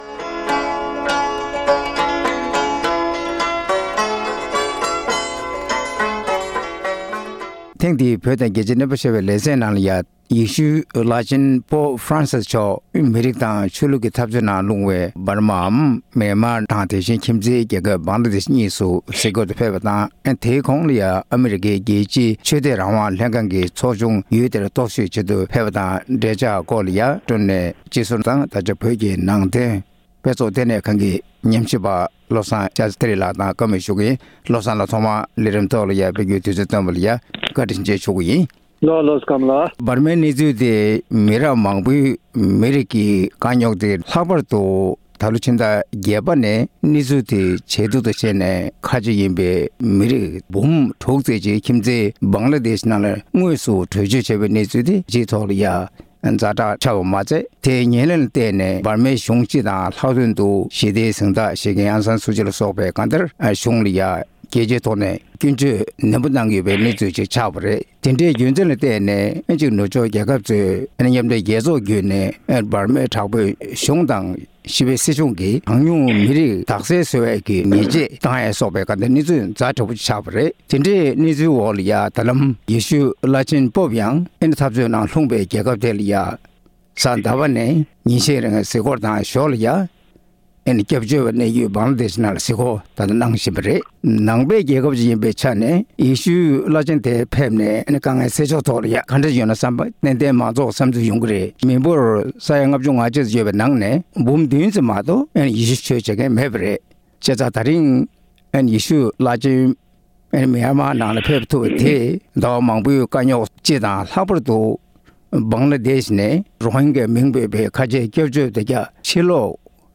གླེང་མོལ་ཞུས་པ་ཞིག